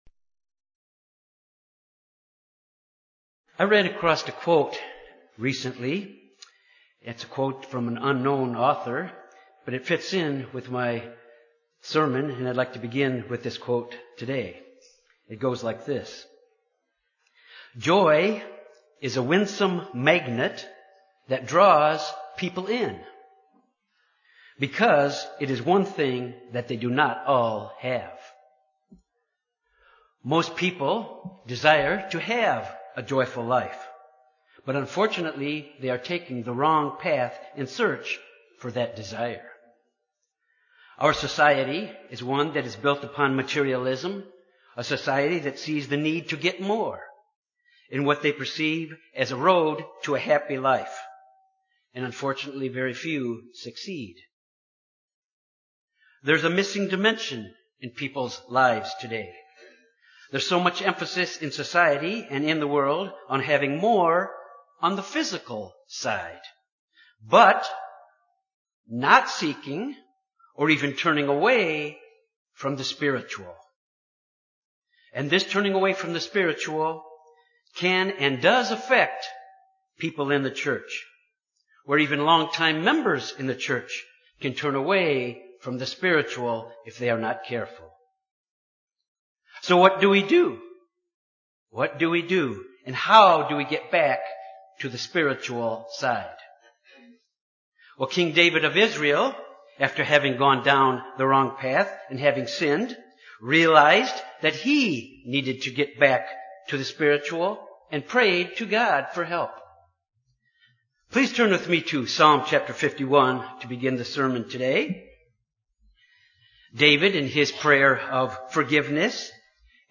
Sermons
Given in Jonesboro, AR Little Rock, AR